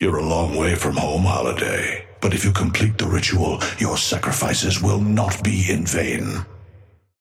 Amber Hand voice line - You're a long way from home, Holliday, but if you complete the ritual, your sacrifices will not be in vain.
Patron_male_ally_astro_start_03.mp3